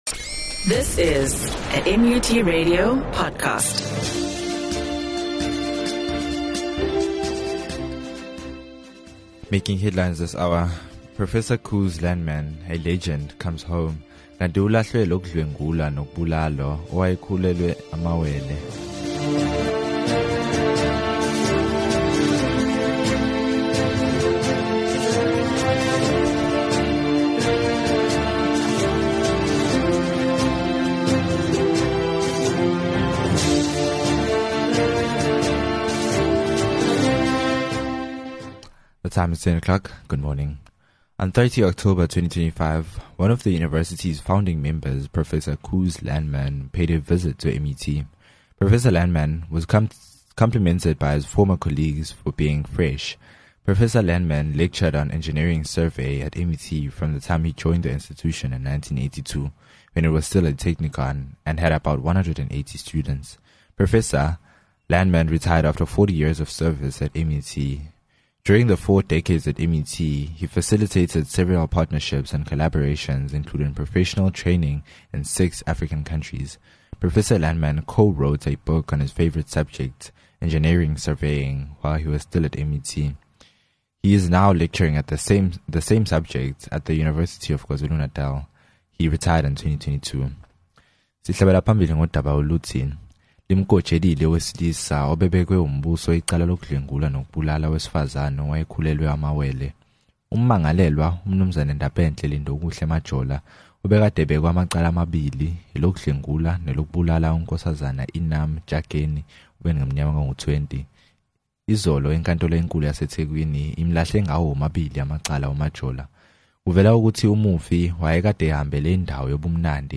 6 Nov MUT News